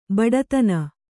♪ baḍatana